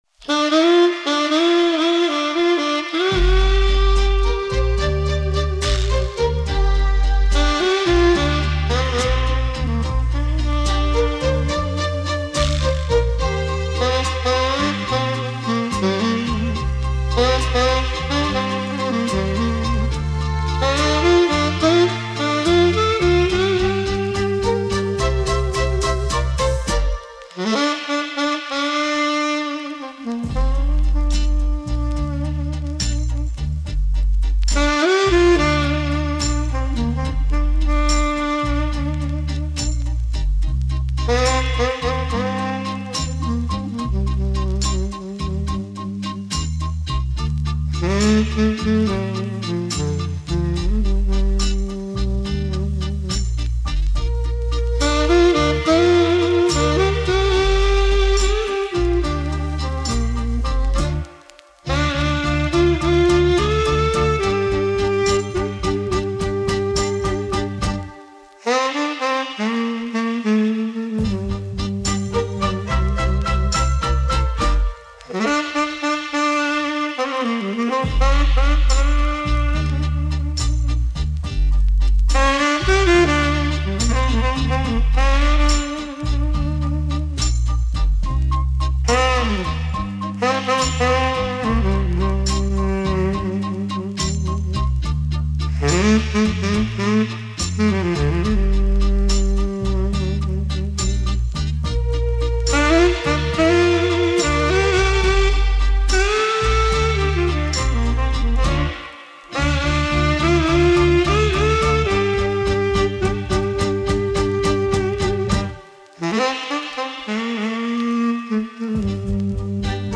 как будто разговаривают два человека, мужчина и женщина,